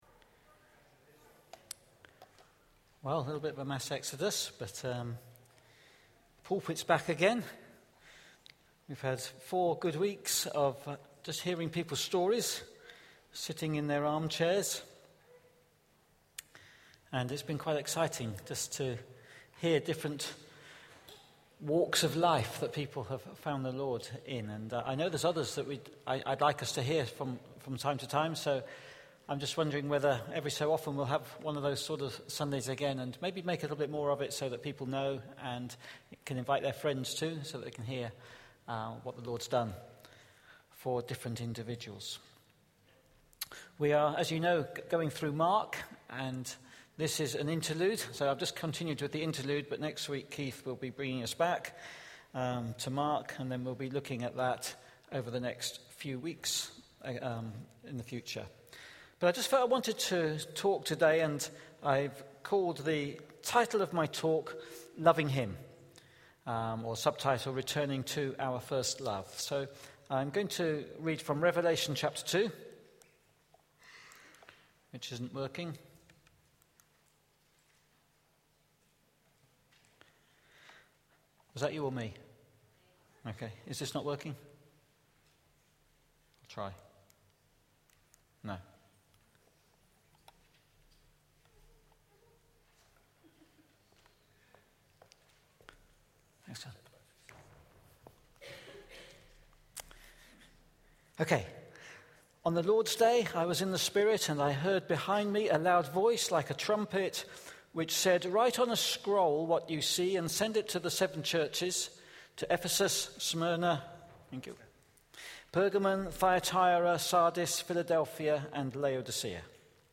Listen back to all Sunday morning talks below.